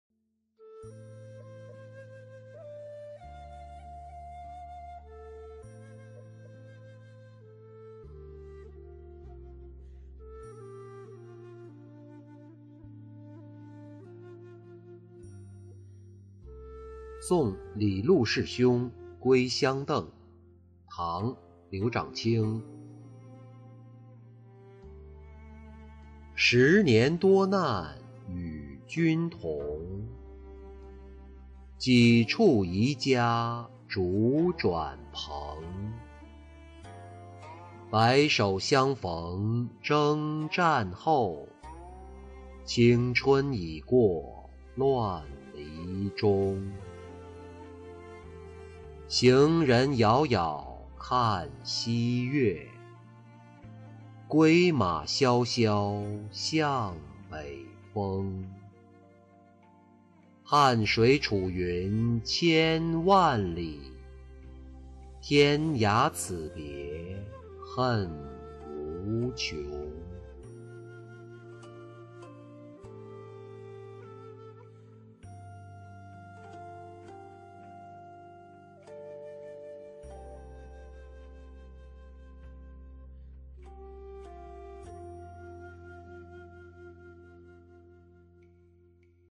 送李录事兄归襄邓-音频朗读